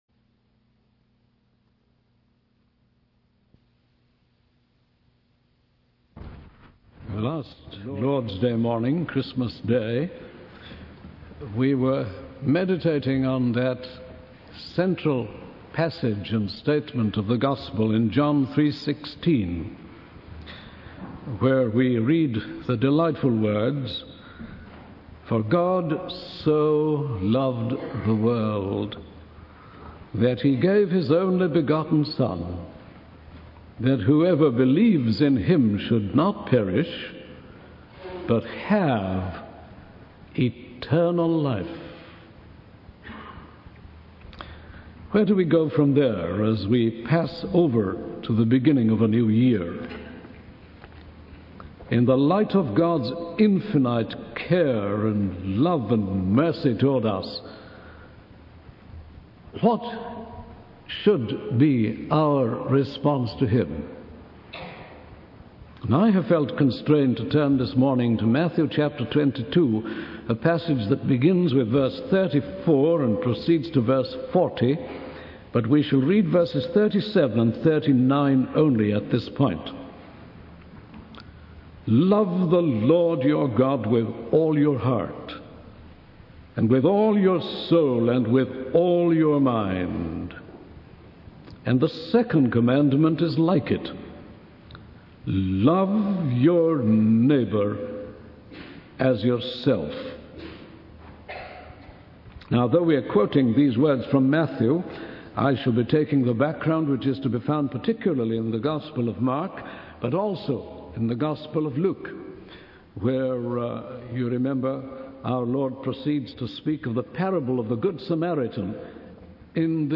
In this sermon, the preacher emphasizes the importance of loving God with all our heart, soul, mind, and strength. He quotes from Mark and Matthew, highlighting the commandment to love God completely. The preacher also emphasizes the need to love our neighbors as ourselves, as a way of expressing our love for God.